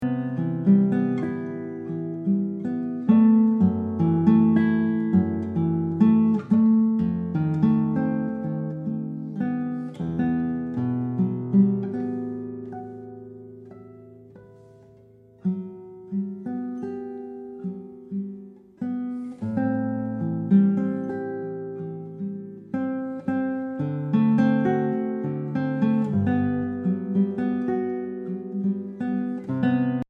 Obras para guitarra